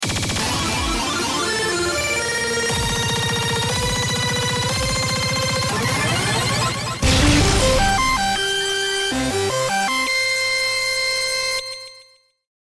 • e牙狼12黄金騎士極限／極限7500バトル勝利音